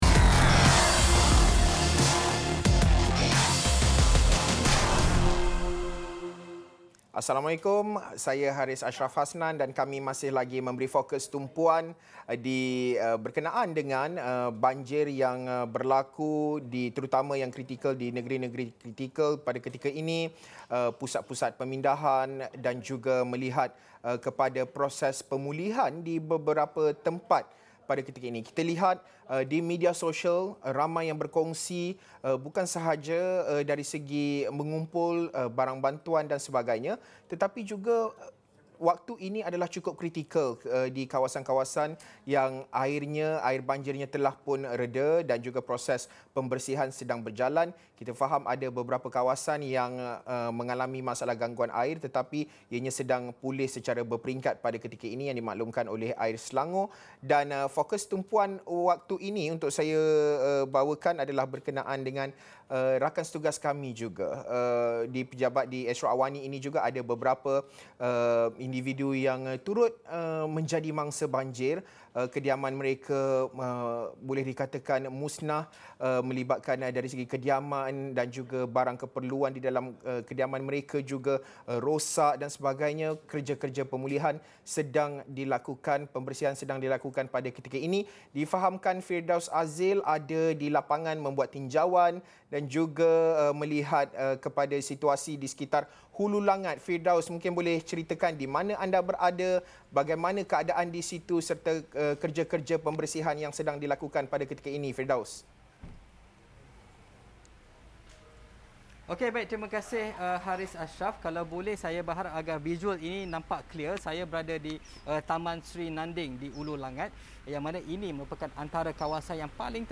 Wartawan Astro AWANI menceritakan kisah di sebalik sesuatu berita yang dilaporkan.